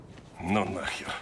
Ну нахер – культовый мем из “Очень страшного кино 2”
Фраза “Ну нахер!” звучит в сцене, где священник заходит в комнату одержимой девушки, видит пугающую картину и в панике убегает, бросая книгу.